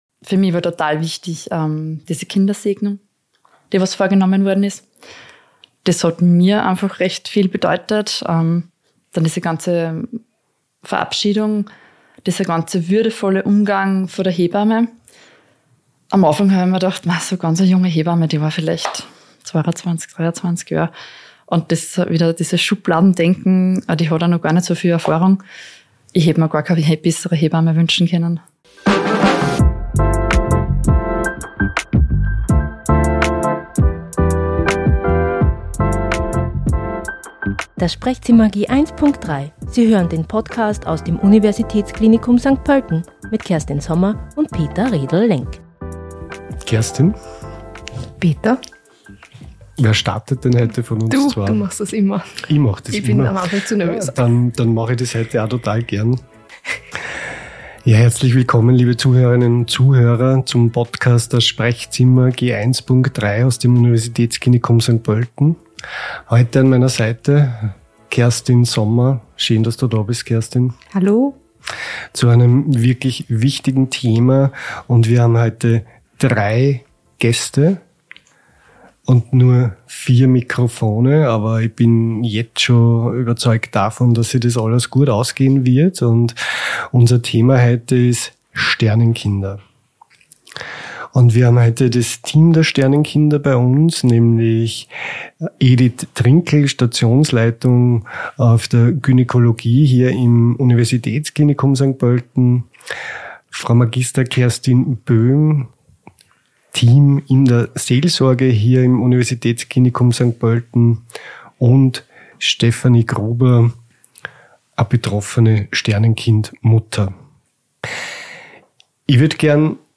Rein fachlich betrachtet geht es beim frühen Verlust des Kindes um Fehl- oder Totgeburt. Unsere Studiogäste